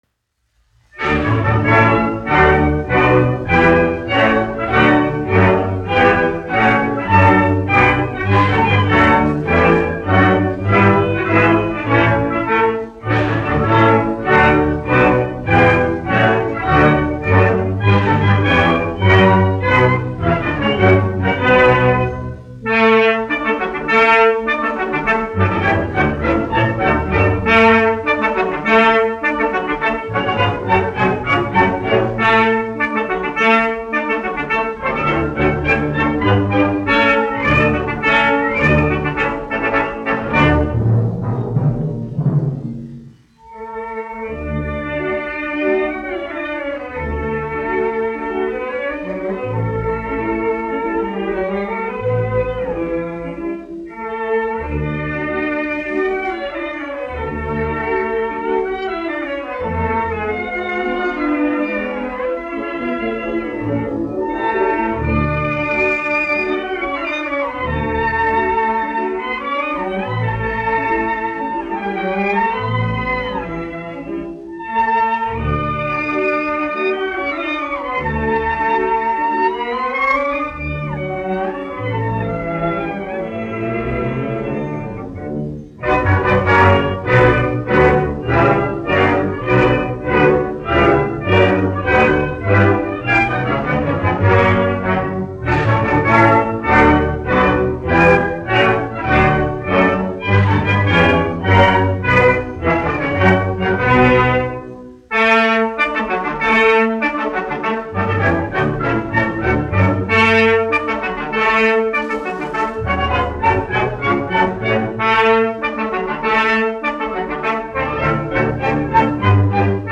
1 skpl. : analogs, 78 apgr/min, mono ; 25 cm
Operas--Fragmenti
Marši
Skaņuplate